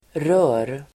Uttal: [rö:r]